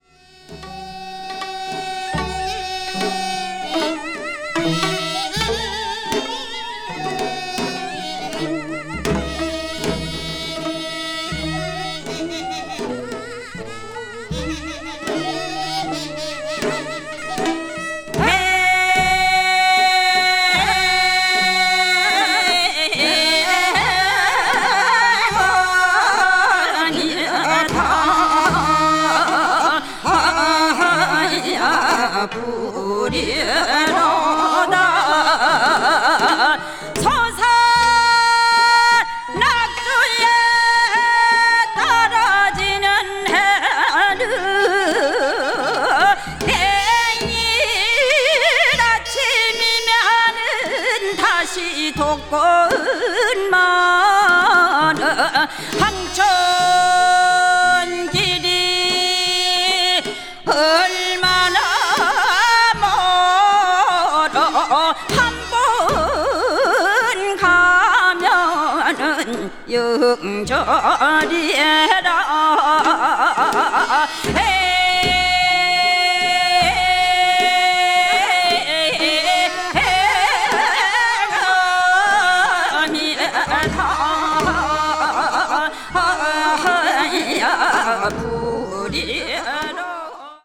media : EX/EX(わずかにチリノイズが入る箇所あり)
粘り気と伸びのあるドスの効いた超絶的歌唱力がまったくもって素晴らしいです。
east asia   ethnic music   folk   korea   south korea   traditional